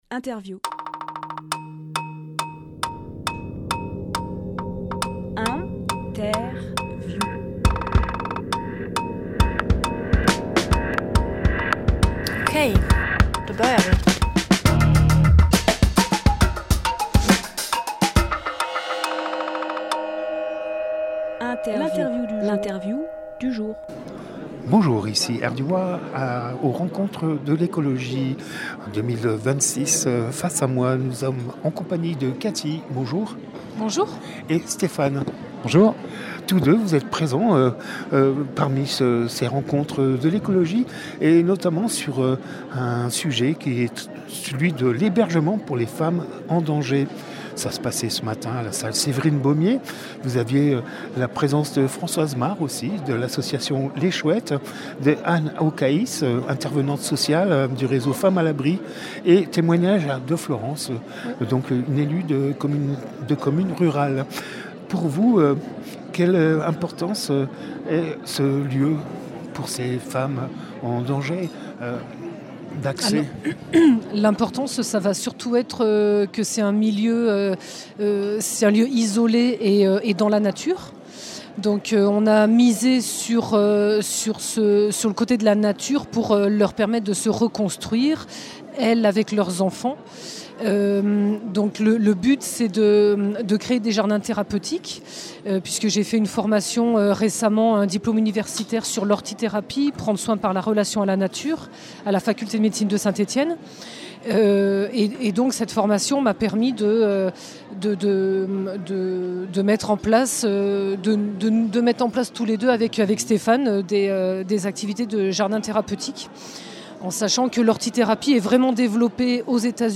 Emission - Interview L’association Théragaïa et son jardin thérapeutique Publié le 28 janvier 2026 Partager sur…